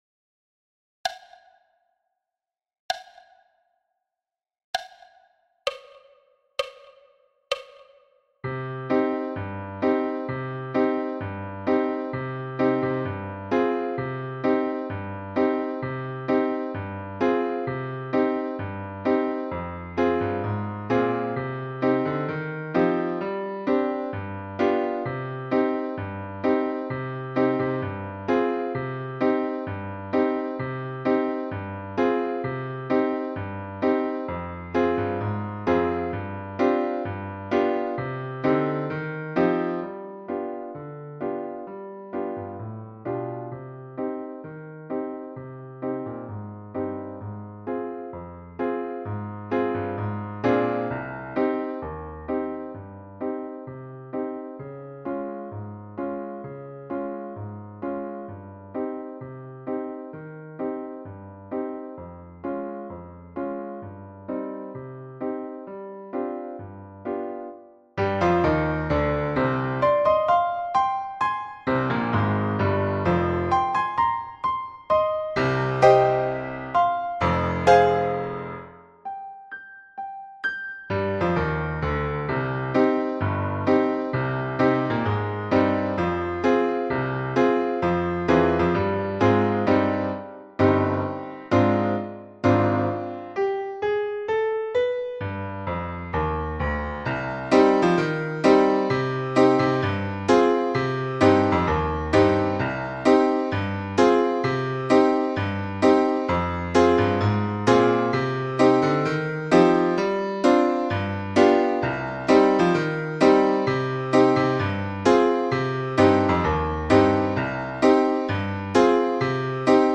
Midi pile – piano à 65 bpm-Part
Midi-pile-piano-a-65-bpm-Part.mp3